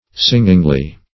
Search Result for " singingly" : The Collaborative International Dictionary of English v.0.48: Singingly \Sing"ing*ly\, adv.